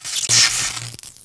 jointeam.wav